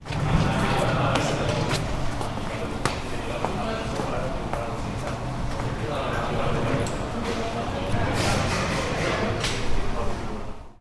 描述：这是UPF图书馆一楼的环境声音。我们听到脚步声和谈话声。这个声音是在UPF的图书馆录制的。
Tag: 校园UPF 图书馆 沉默 UPF-CS13 环境